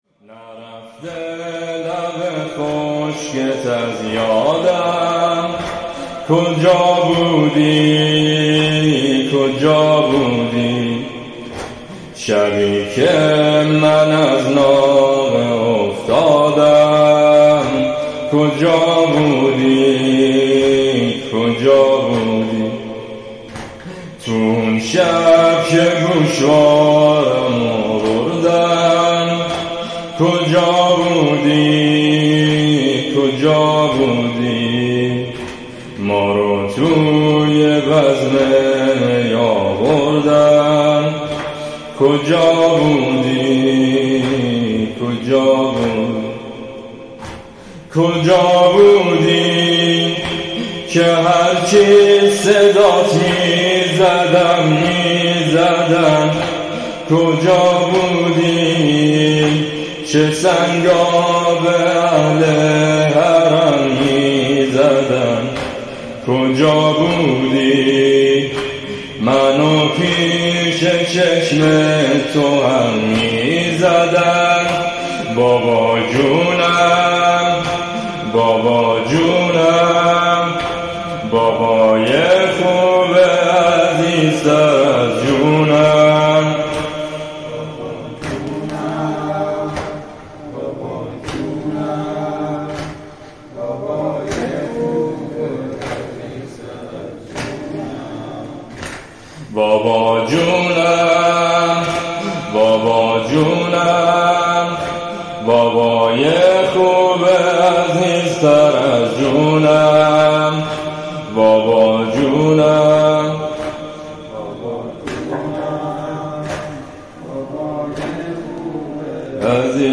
شب سوم محرم